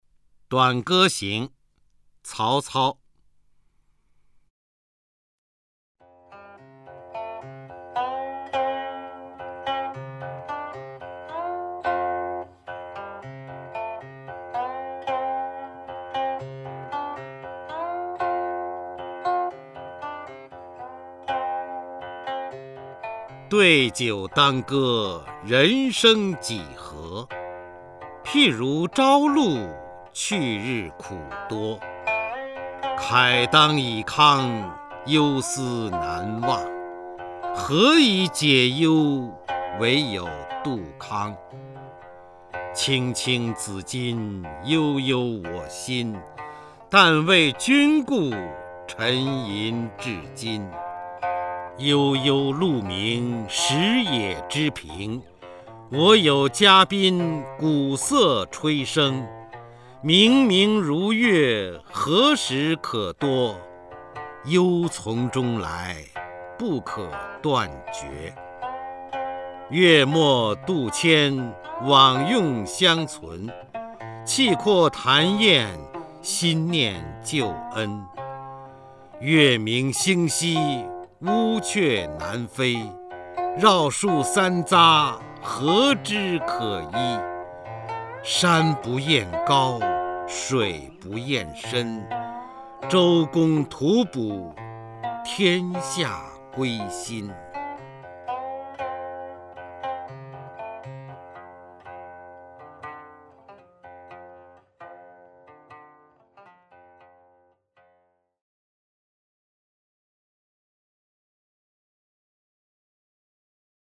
[7/11/2009]对酒当歌，人生几何？ 方明配乐朗诵曹操诗作《短歌行》 激动社区，陪你一起慢慢变老！